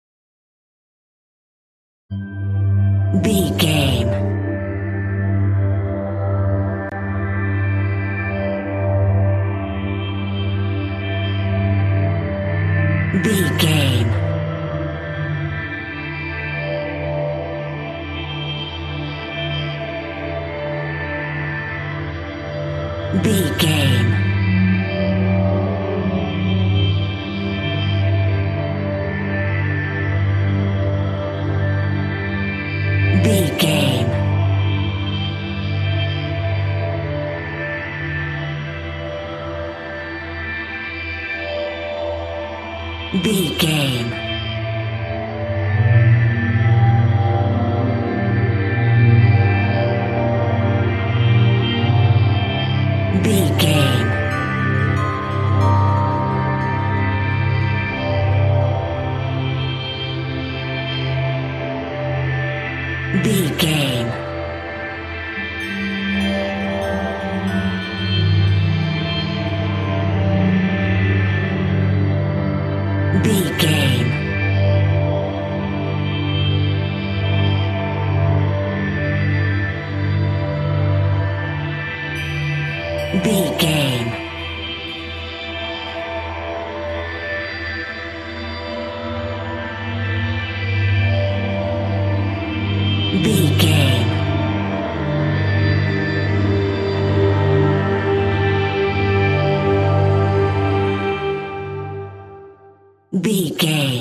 Haunted Atmosphere.
Atonal
tension
ominous
dark
suspense
eerie
synthesiser
Synth Pads
Synth Strings
synth bass